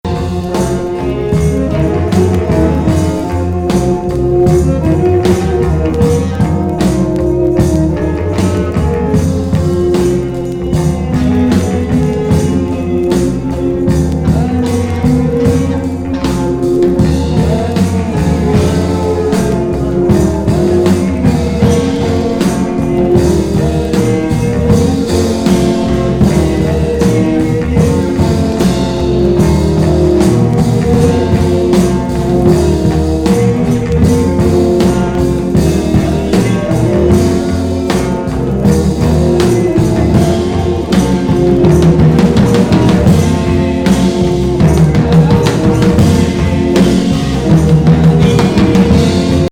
スウェーデン産オカルティック・サイケ69年作!